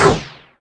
VEC3 Percussion
VEC3 Percussion 055.wav